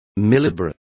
Complete with pronunciation of the translation of millibars.